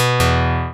Good start on default sound theme.
ScreenReaderOff.wav